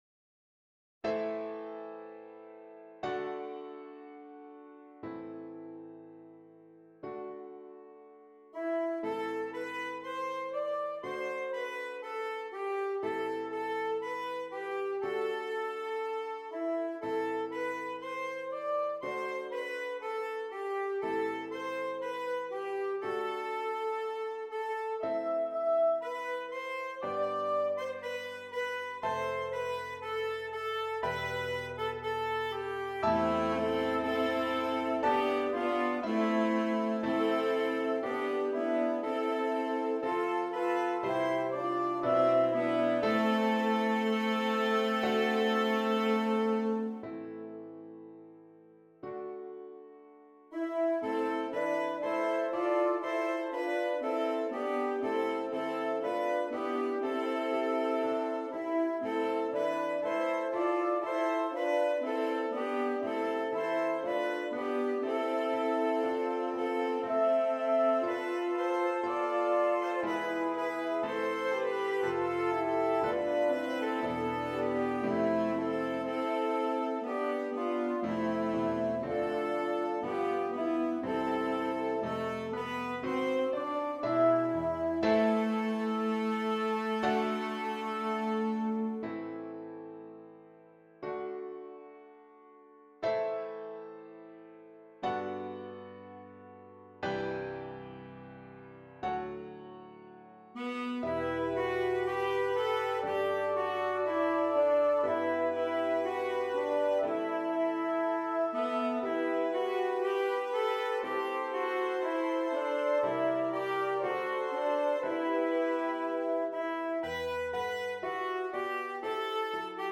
Christmas
3 Alto Saxophones and Keyboard
Traditional